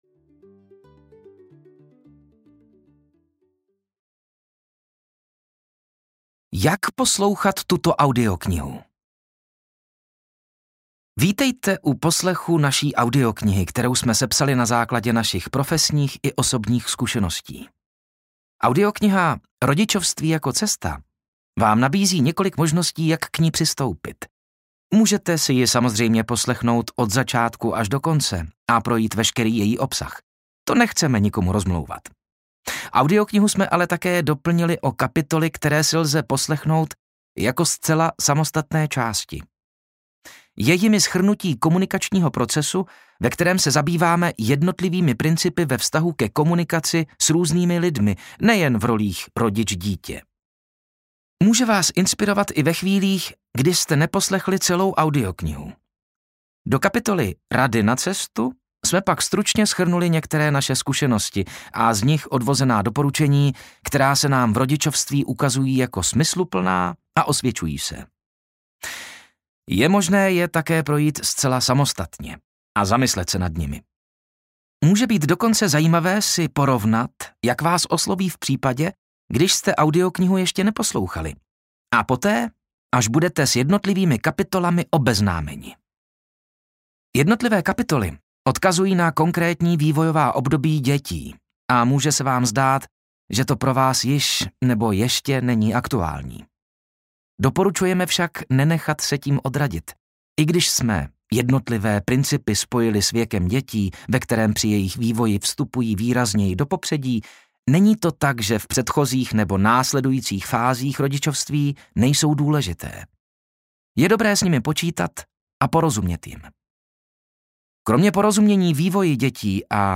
Rodičovství jako cesta audiokniha
Ukázka z knihy